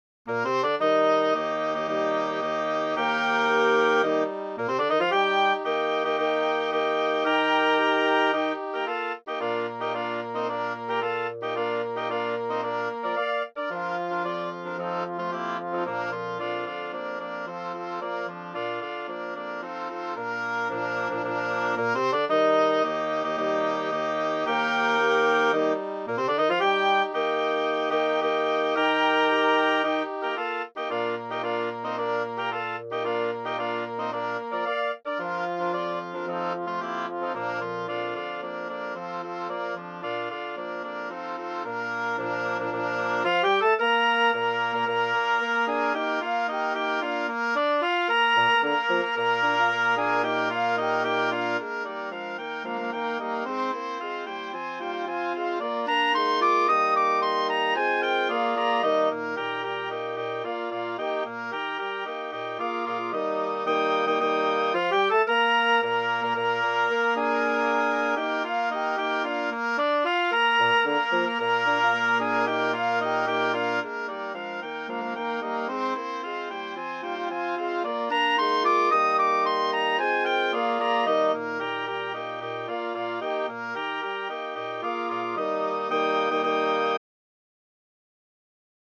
WIND OCTET
2 OBOES, 2 CLARINETS, 2 HORNS, 2 BASSOONS